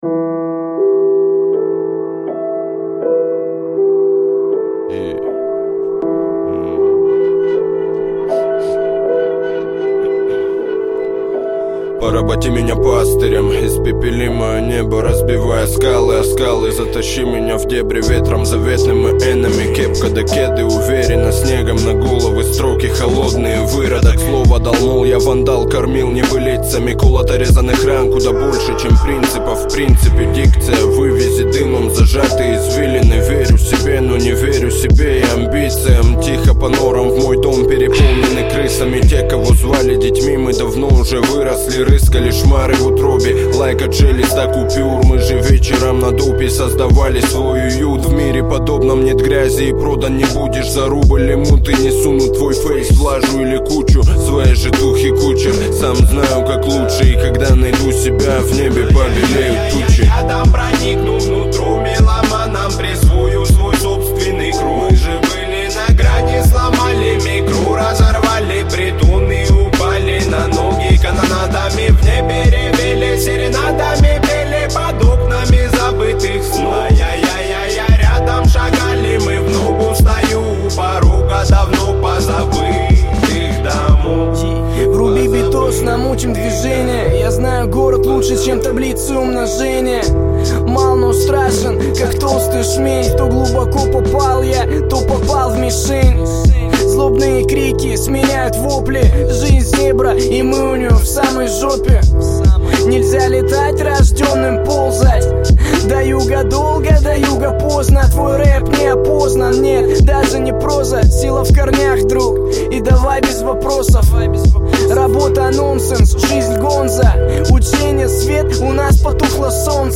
Русский рэп
Жанр: Жанры / Русский рэп